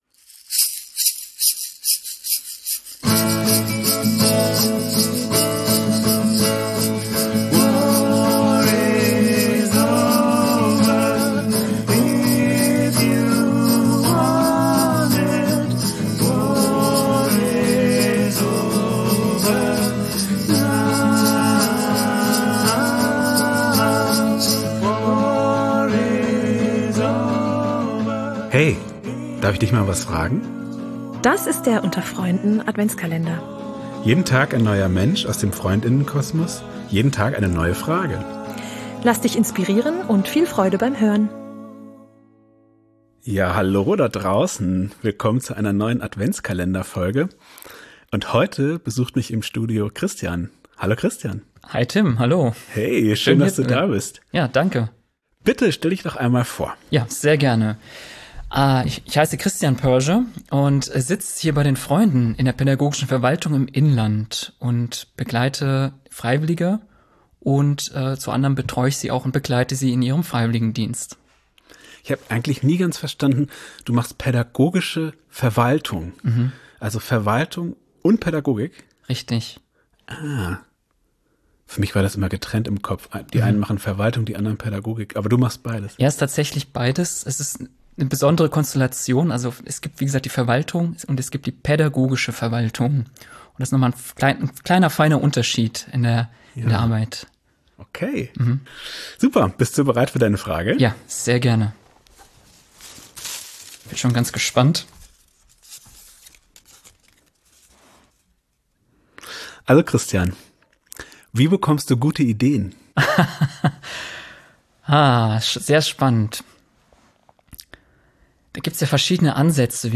In unserem Adventskalender zum Hören wird jeden Tag eine andere Person aus dem Freund*innen-Kosmos zu einer persönlich-philosophischen Frage kurz interviewt und darf spontan dazu antworten.